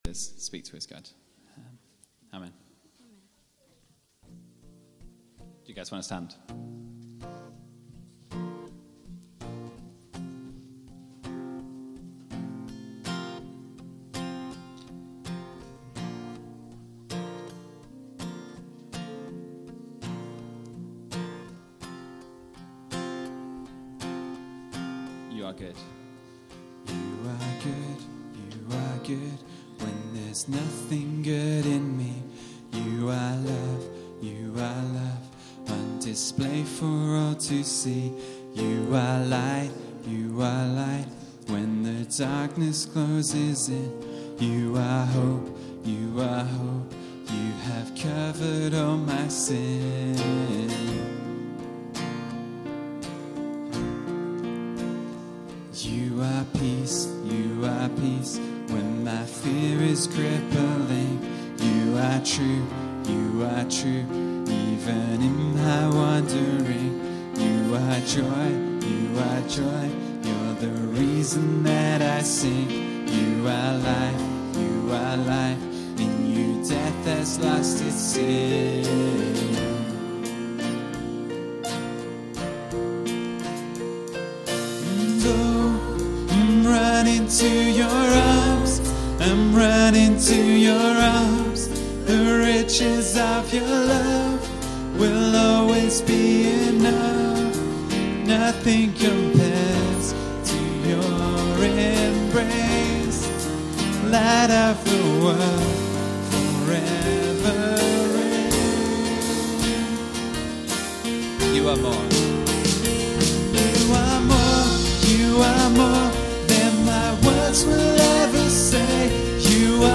Download Filename 130602Worship.mp3 filesize 40 MB Version 1.0 Date added 1 January 2015 Downloaded 1198 times Category Worship Sets Tags 2013
worshipteam13